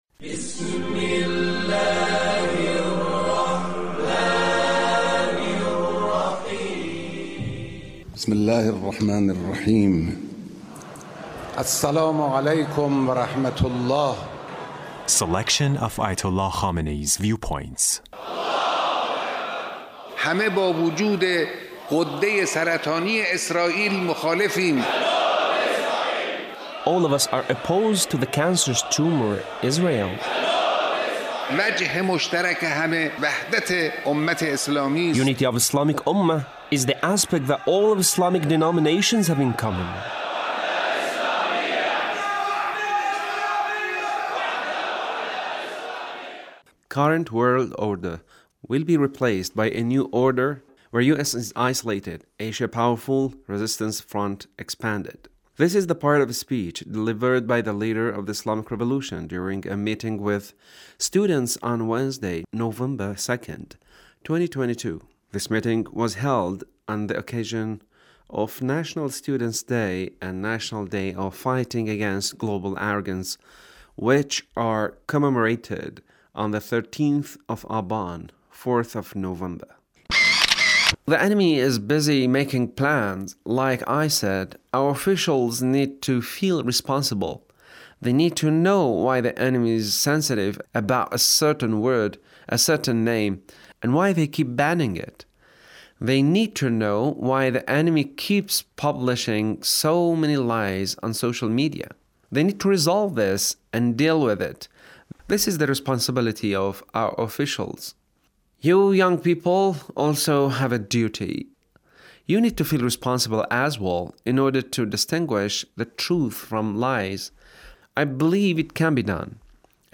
Leader's Speech on 13th of Aban